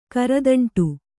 ♪ karadaṇṭu